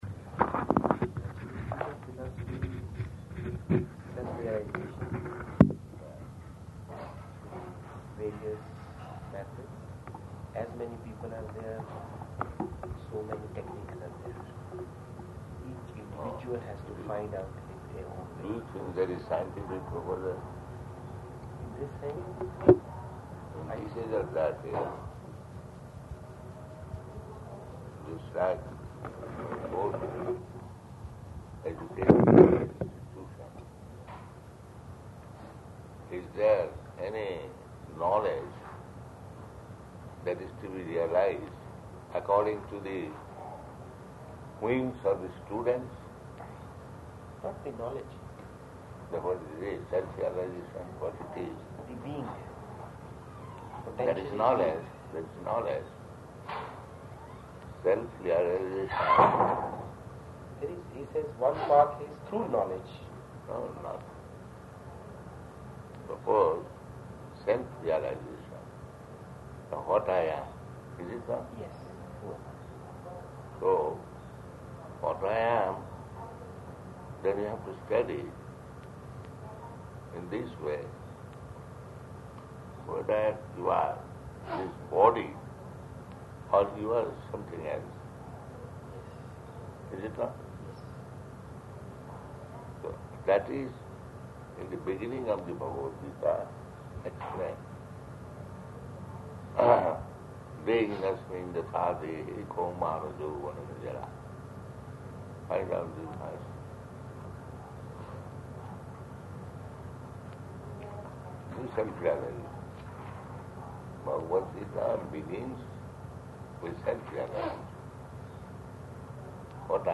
Room Conversation with Indian Man
Room Conversation with Indian Man --:-- --:-- Type: Conversation Dated: December 22nd 1976 Location: Poona Audio file: 761222R2.POO.mp3 Indian man: ...self-realization.